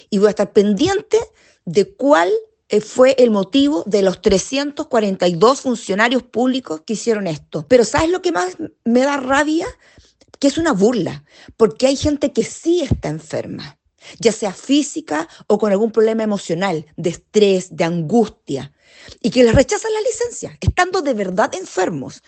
La concejala Claudia Peñailillo señaló que es lamentable que estos funcionarios se aprovechen, considerando que hay tantas personas que necesitan sacar una licencia y no pueden.